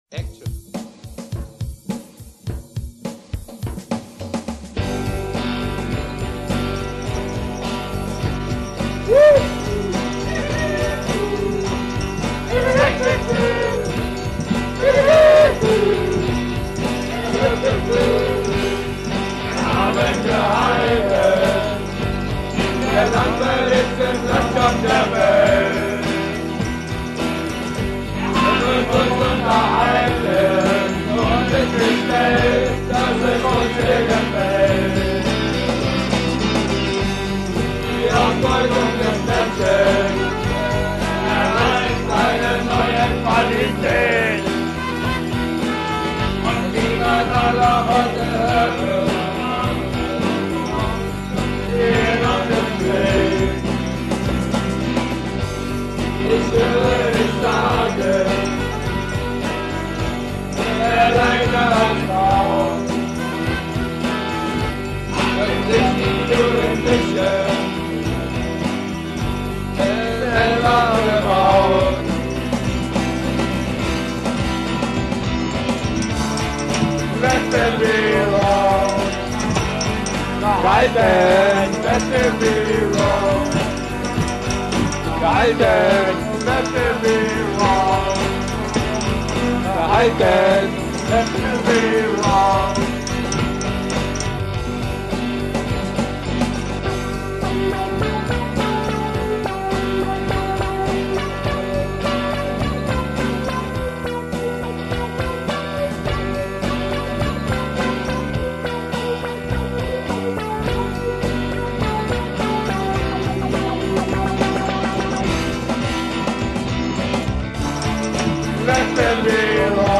Die erste Session 1999